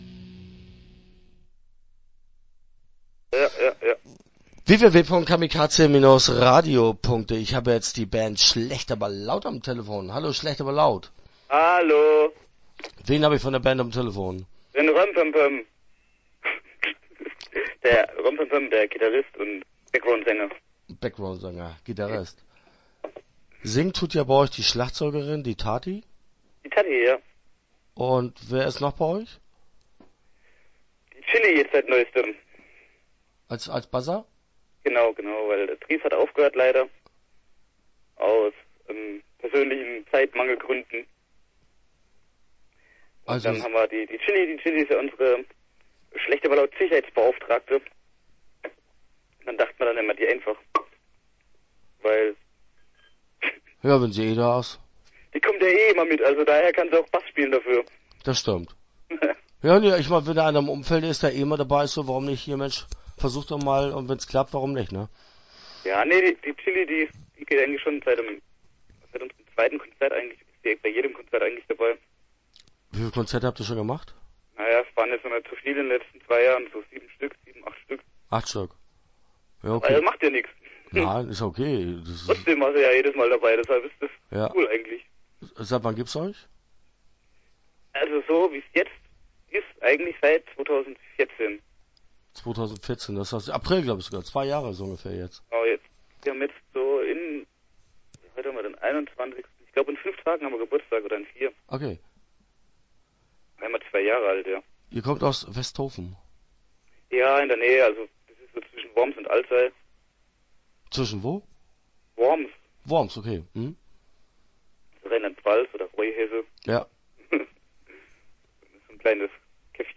Schlecht aber Laut - Interview Teil 1 (10:41)